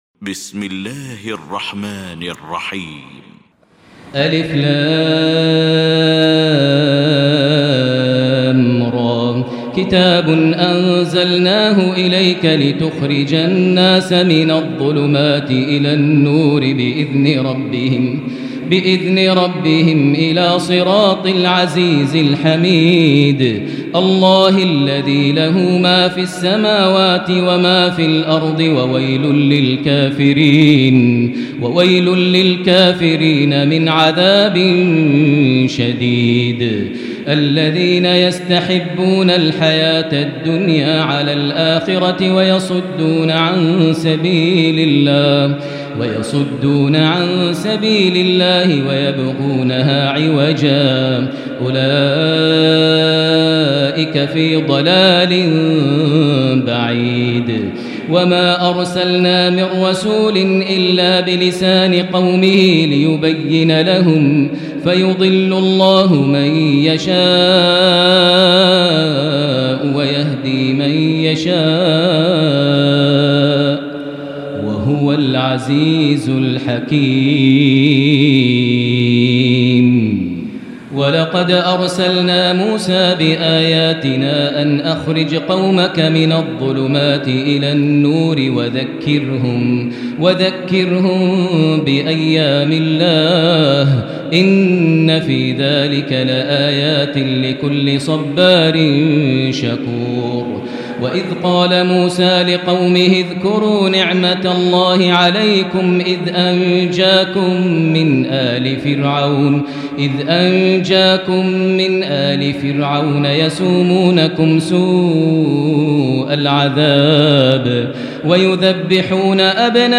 المكان: المسجد الحرام الشيخ: معالي الشيخ أ.د. بندر بليلة معالي الشيخ أ.د. بندر بليلة فضيلة الشيخ ماهر المعيقلي إبراهيم